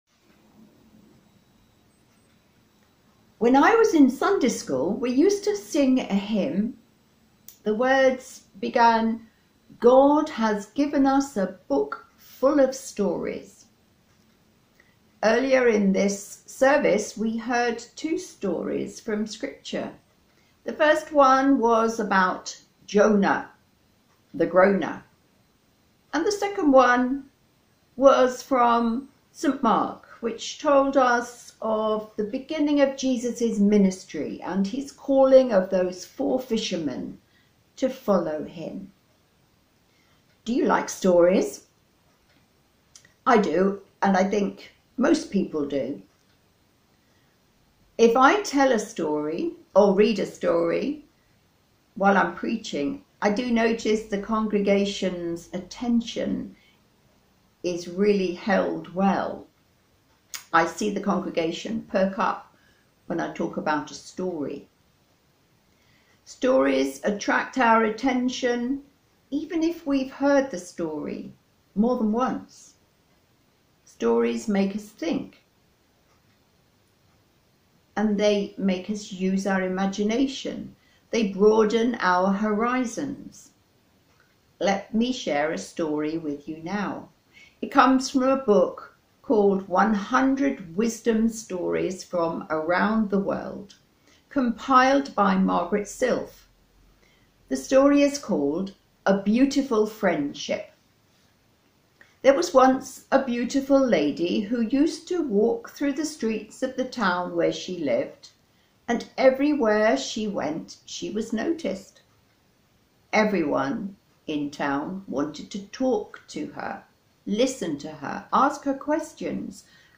latestsermon-3.mp3